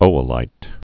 (ōə-līt)